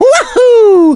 One of two voice clips from Mario in Super Mario Galaxy when he back flips.
SMG_Mario_Wahoo_(backflip).wav